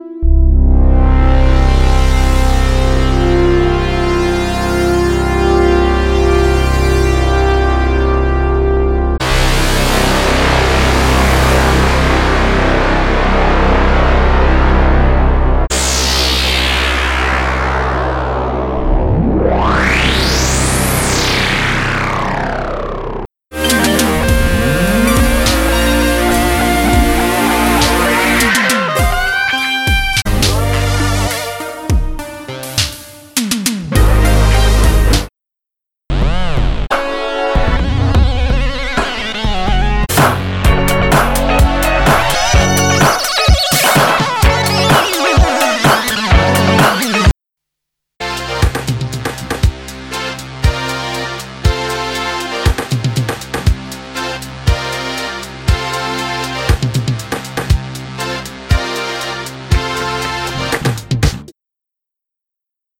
复音合成器 AlyJamesLab OB-XTRM 2[WIN] 2.0-音频fun
之声 当你拿8个单声道合成器并一起使用它们时会发生什么？巨大的模拟声音！
和我一起裸露，试图保持这个夏天的发布日期，因为一个人的行动是战争:)至少你已经可以设计声音，并用一些黄铜刺，切割引线，郁郁葱葱的垫子和尖叫的低音来搞笑它。
2Pole滤波器基于OB-X，4Pole基于CEM3320实现，2pole在带宽上具有经典的SVF线性“歌唱”谐振，而4pole在较低的截止值下将产生较少的共振（有点像Moog），带通输出也提供2极模式，而4pole具有可选的音量损失补偿。